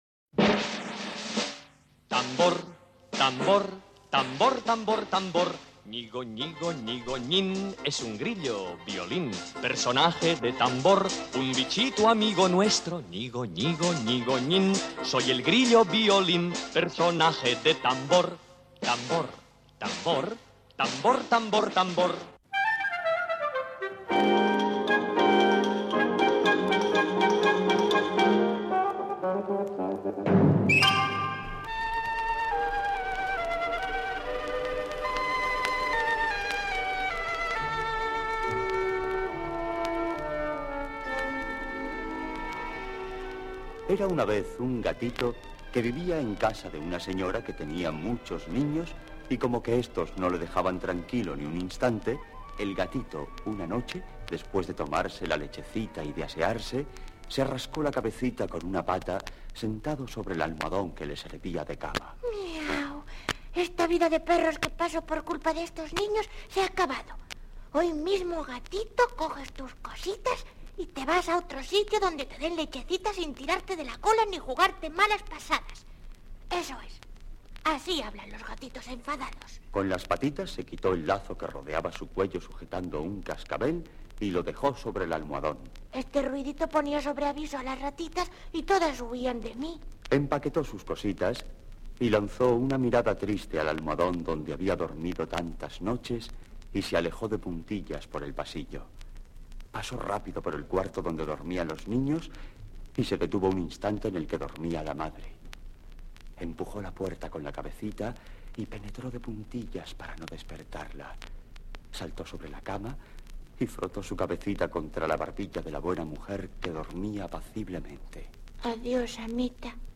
65b414b58d3dbb4b7bc8147f123f58059dbbf126.mp3 Títol Ràdio Barcelona Emissora Ràdio Barcelona Cadena SER Titularitat Privada estatal Nom programa Tambor Descripció Careta del programa, cantada per Salvador Escamilla, i capítol "El gatito travieso".
Infantil-juvenil